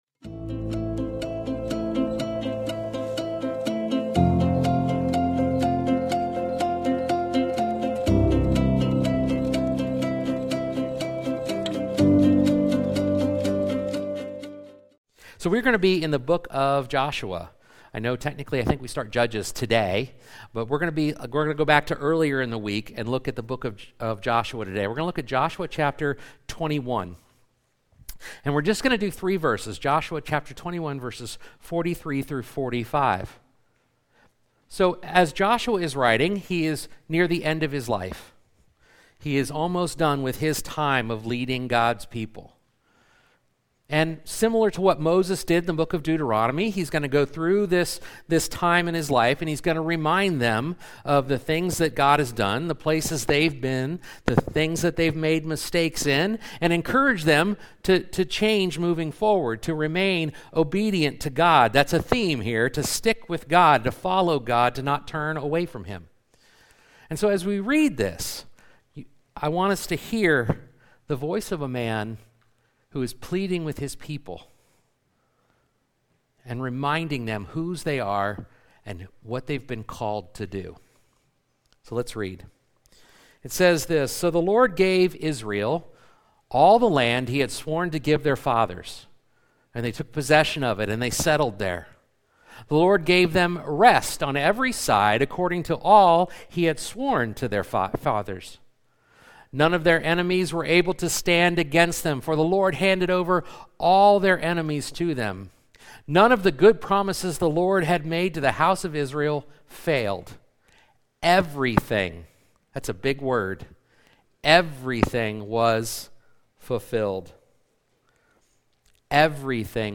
Sermons | Gretna Brethren Church